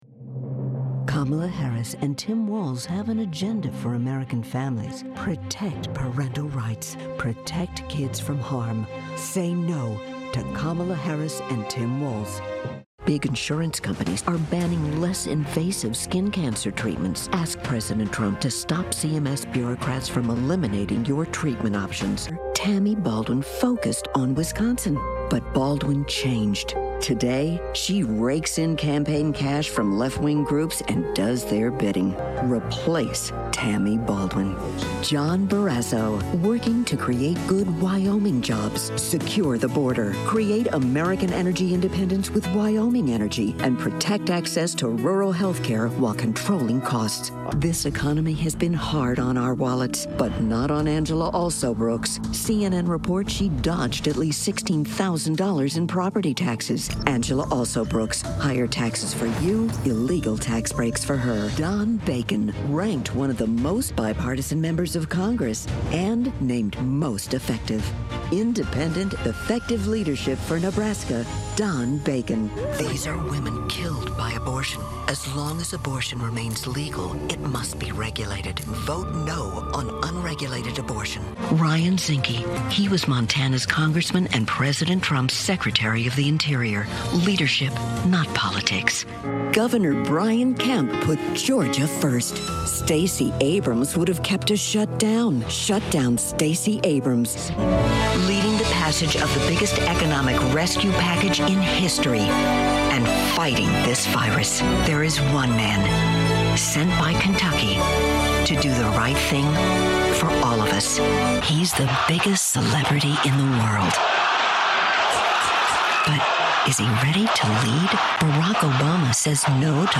Political TV Affiliates Commercials Animation Narration VIDEO (audio, video not playing?
politicaldemowith2024.mp3